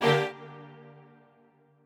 strings1_36.ogg